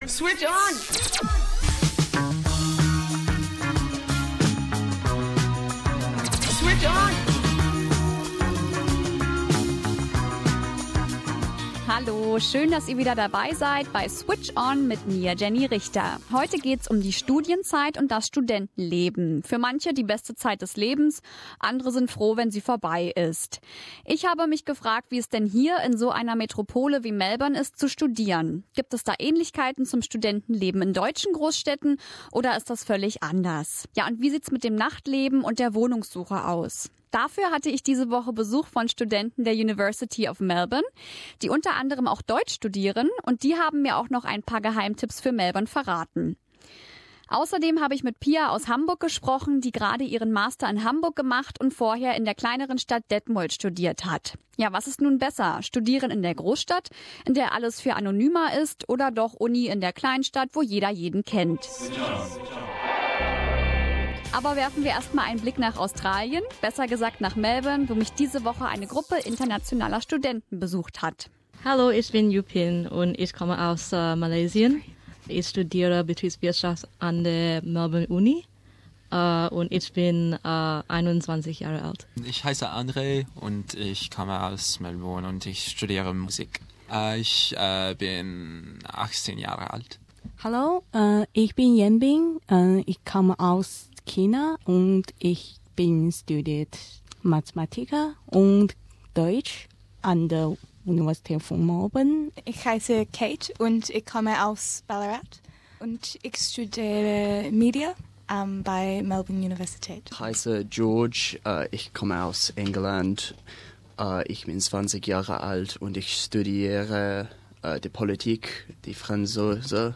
Students from the "University of Melbourne" talk about their life in the most livable city in the world. How can German cities keep up with Melbourne?